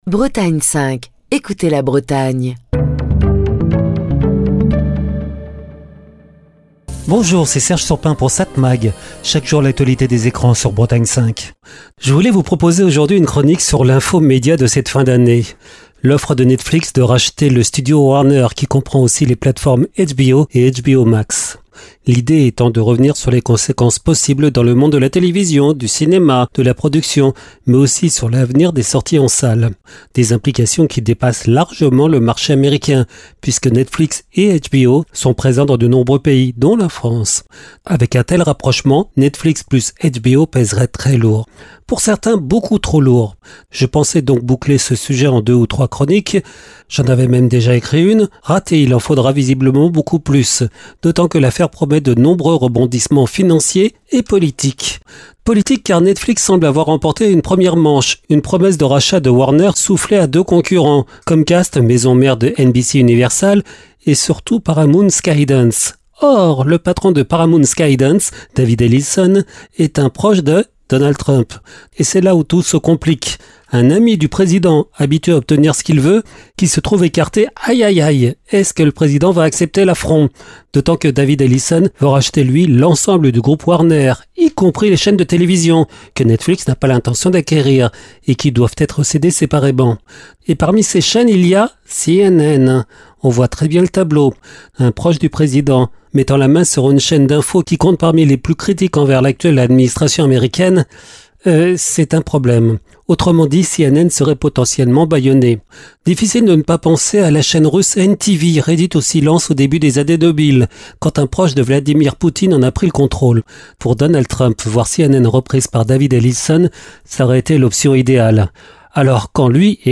Chronique du 9 décembre 2025.